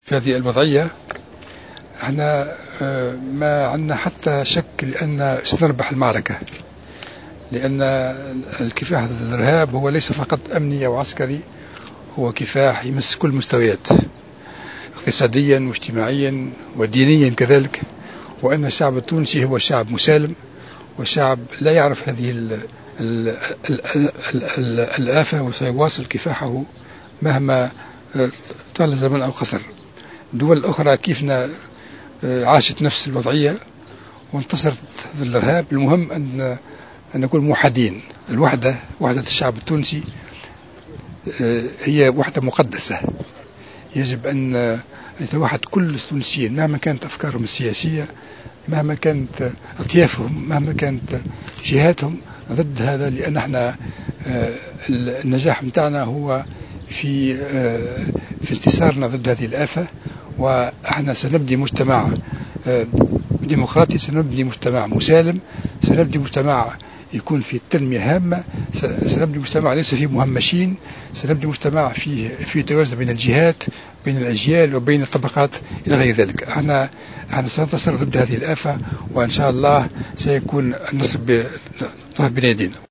قال وزير الدفاع الوطني، فرحات الحرشاني في تصريح للجوهرة أف أم اليوم الاربعاء، على هامش إشرافه على تأبين شهداء الجيش الخمسة بثكنة عقبة بن نافع بالقيروان الذين سقطوا أمس الثلاثاء، إن الإرهاب لن يحط من معنويات المؤسسة العسكرية ولا من معنويات الشعب التونسي.